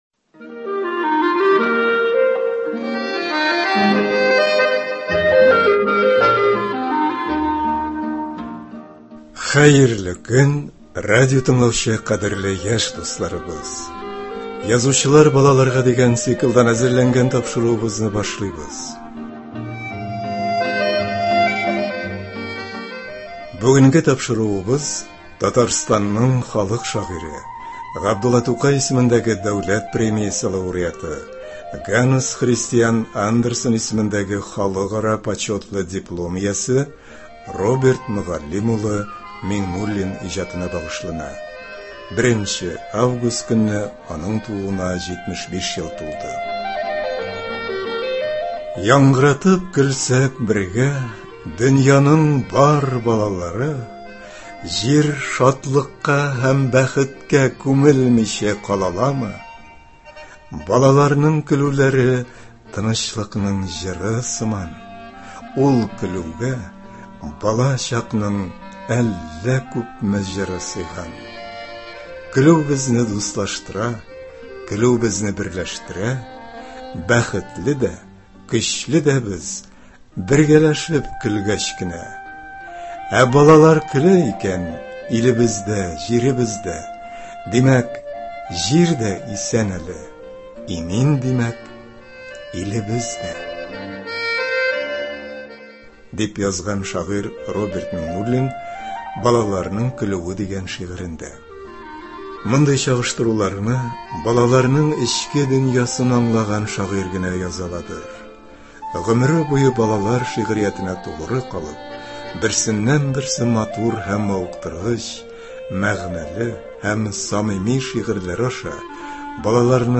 Бүгенге тапшыруыбыз Татарстанның халык шагыйре, Габдулла Тукай исемендәге Дәүләт премиясе лауреаты Роберт Мөгаллим улы Миңнуллин иҗатына багышлана. 1 август көнне аның тууына 75 ел тулды. Тапшыру барышында автор белән 2018 елда язып алынган әңгәмә дә ишетерсез.